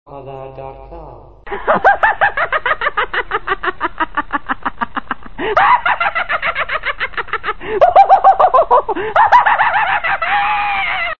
Laughing Voice Ringtone
• Funny Ringtones